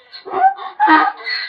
PixelPerfectionCE/assets/minecraft/sounds/mob/horse/donkey/hit2.ogg at f70e430651e6047ee744ca67b8d410f1357b5dba